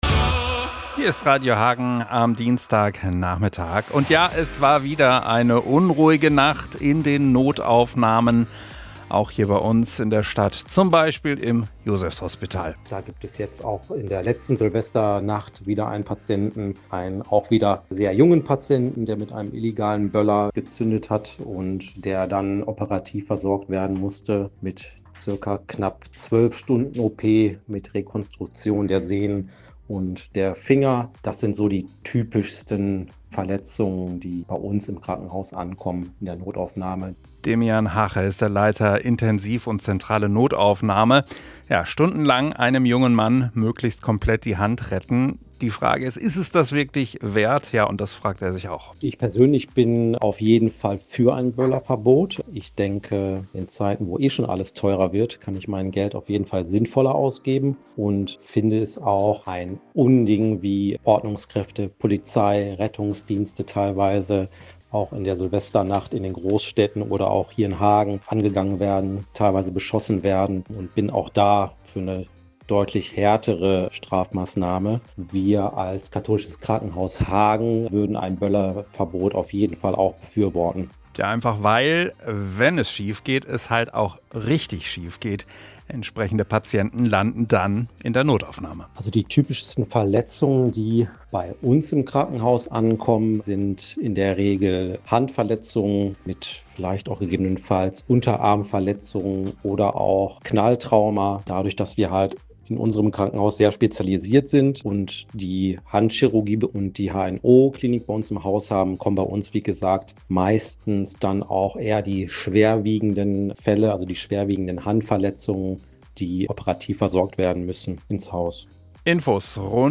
MITSCHNITT AUS DER SENDUNG
off-air-record-studio-1-20.mp3